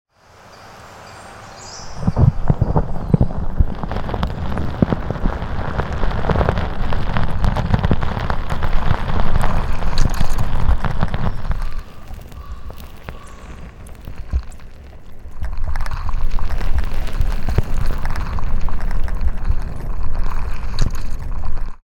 One mission Live from Bowen Island. The banana slug opens up about life, slime, and staying smooth under pressure.